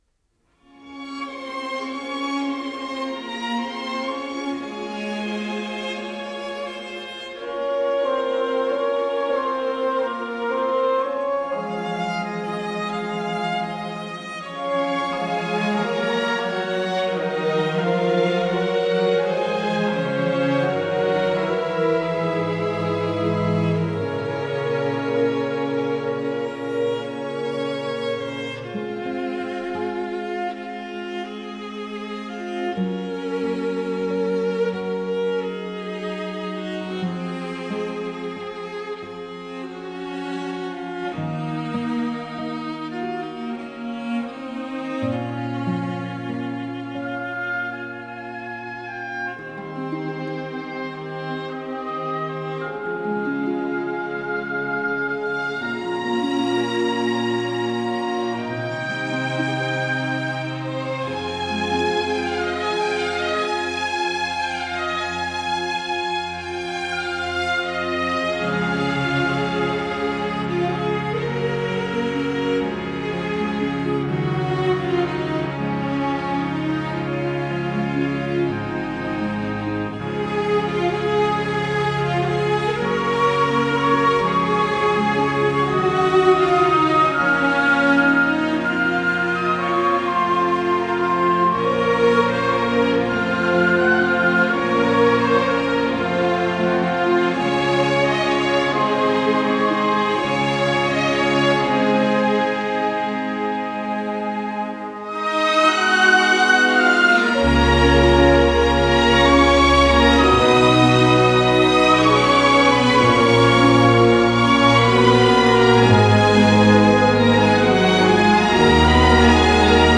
آهنگ بی کلام